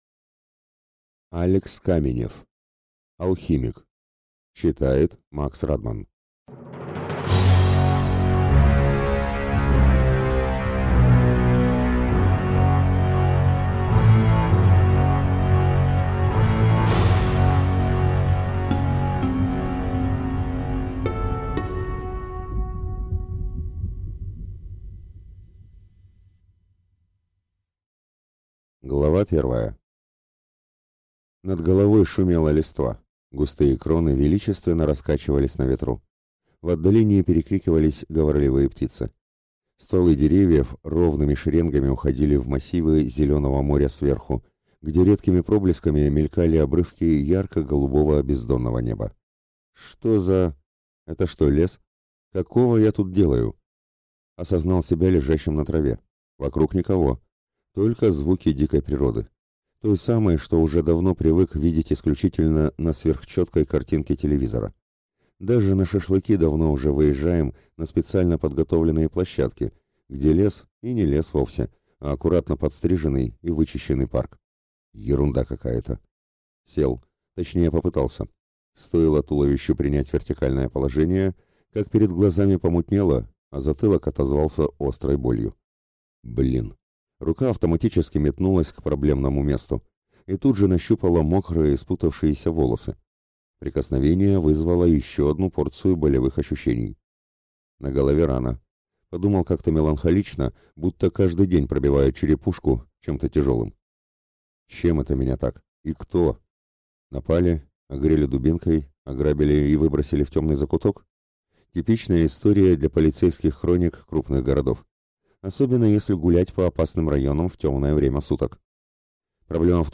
Аудиокнига Алхимик | Библиотека аудиокниг